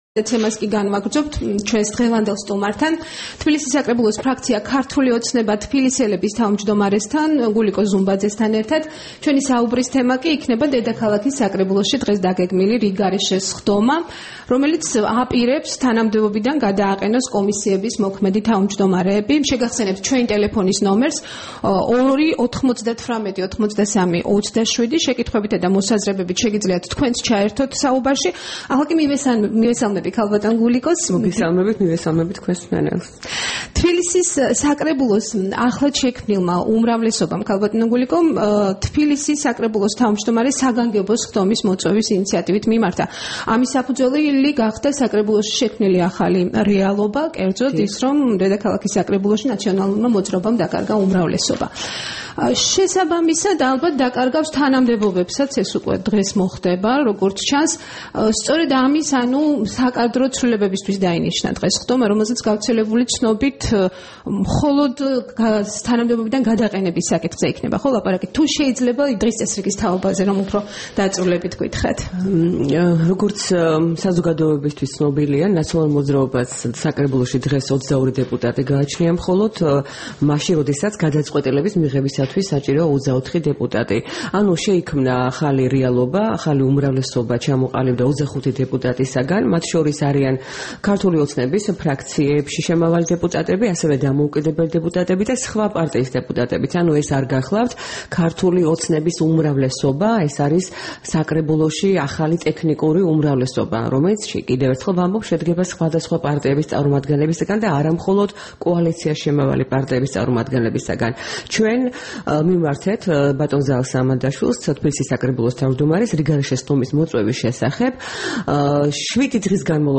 საუბარი გულიკო ზუმბაძესთან